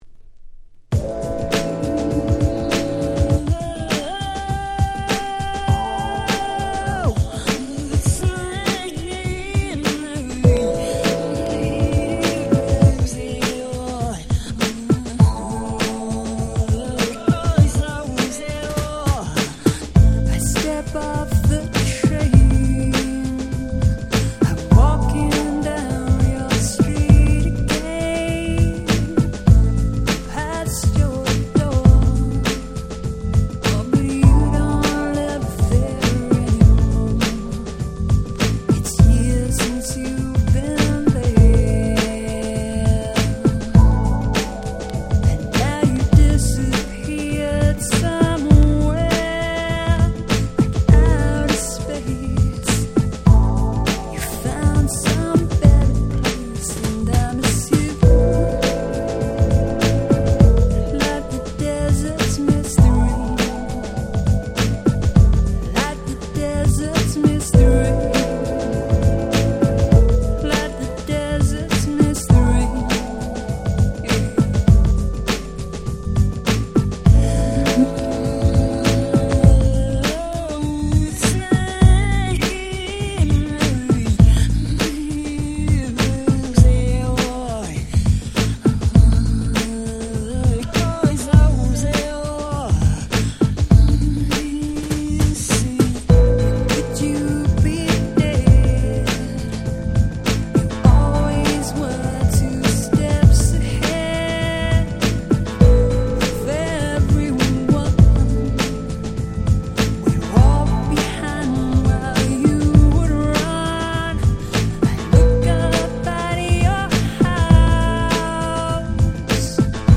94' Super Hit R&B / Pops / Vocal House !!